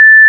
Misc (2): coin_collect, level_up
**⚠  NOTE:** Music/SFX are PLACEHOLDERS (simple tones)
coin_collect.wav